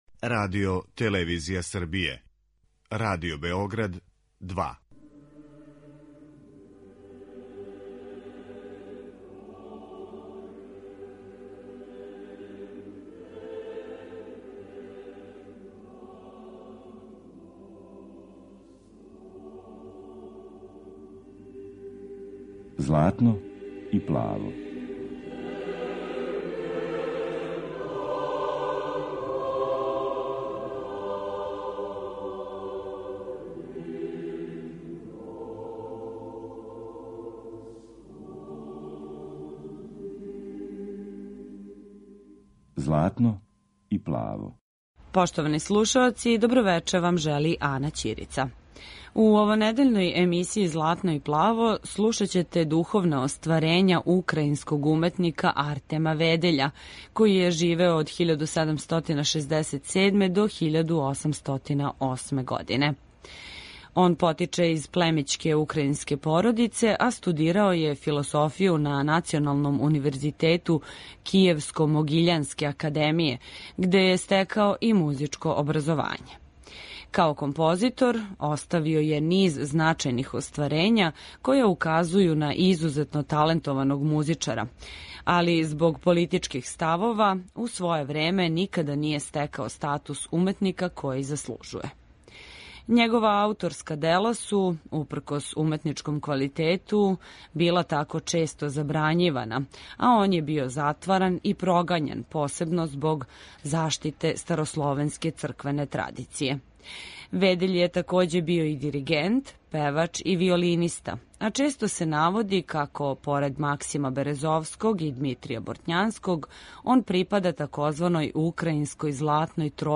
Духовна дела
Емисија посвећена православној духовној музици.